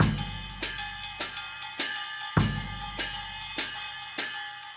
Cymbal and Kick 06.wav